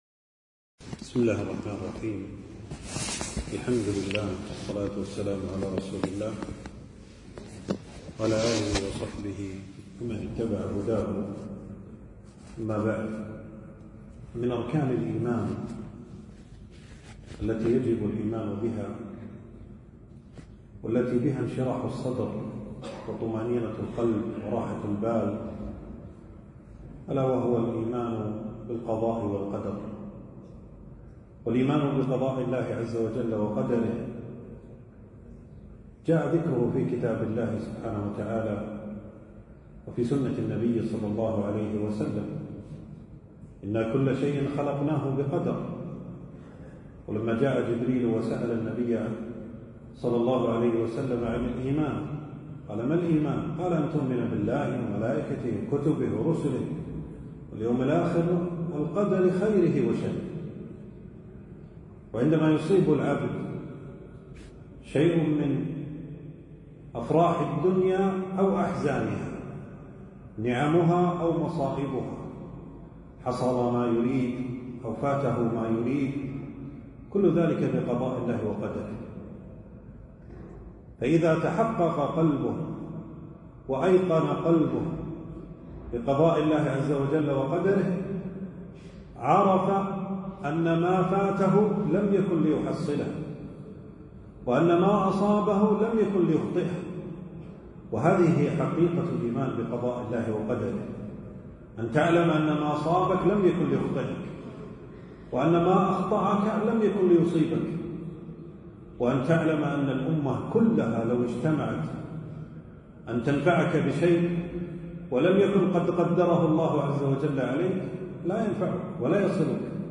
تنزيل تنزيل التفريغ كلمة بعنوان: من آمن بقضاء الله وقدره اطمأن قلبه وانشرح صدره.
في مسجد أبي سلمة بن عبدالرحمن.